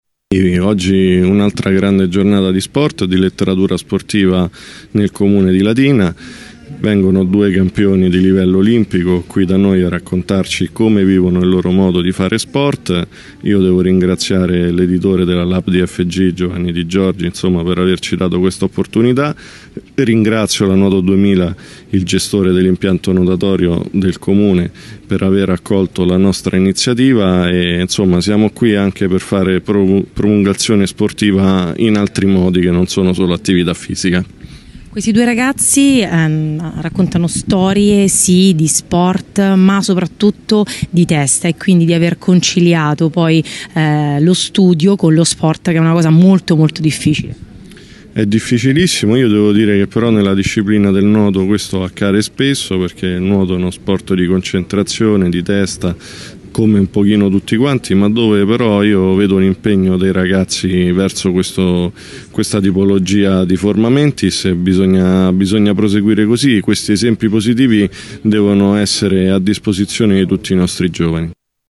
Presente l’assessore allo Sport del Comune di Latina Andrea Chiarato